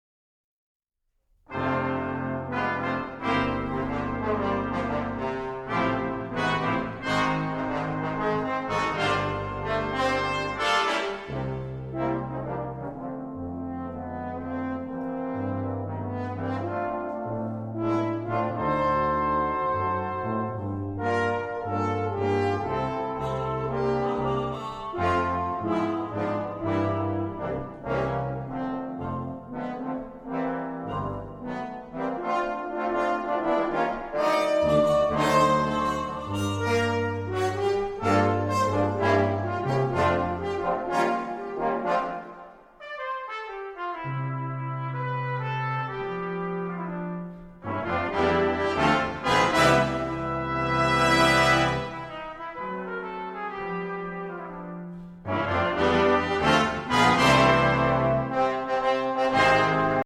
Tentet - Giant Brass